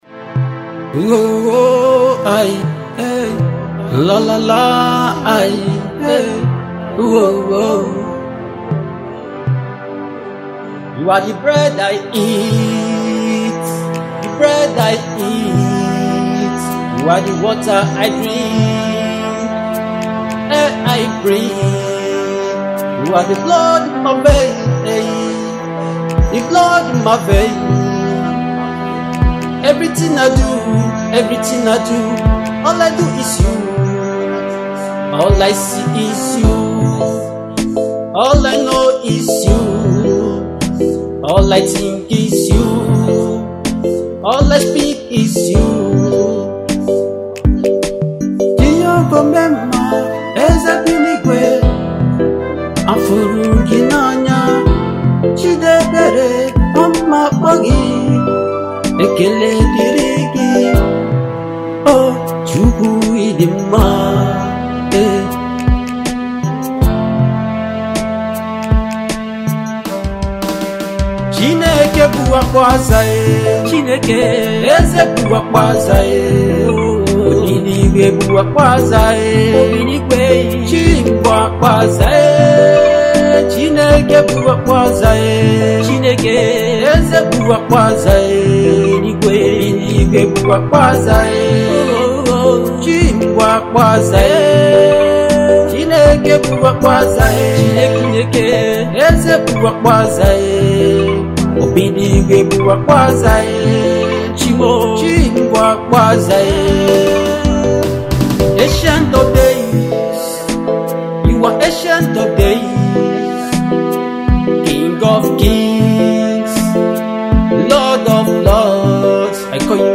Fast rising Nigerian gospel music minister
inspirational single